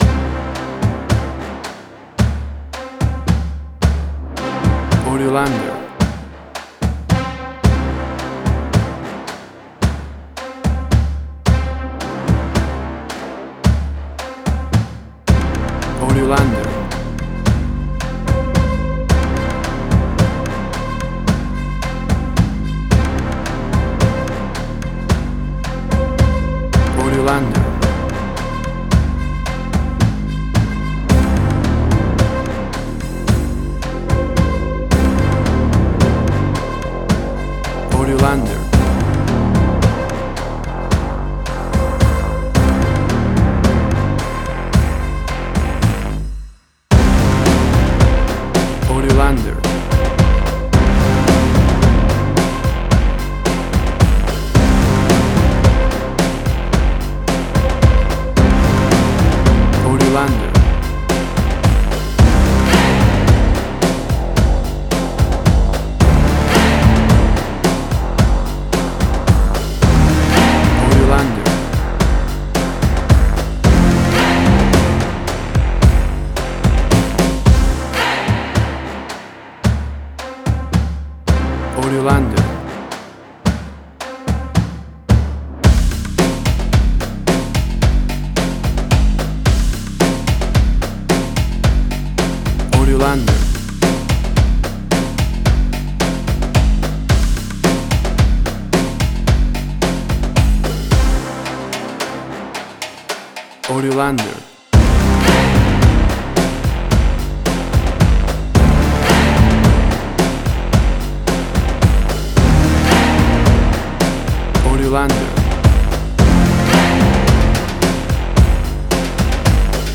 WAV Sample Rate: 16-Bit stereo, 44.1 kHz
Tempo (BPM): 110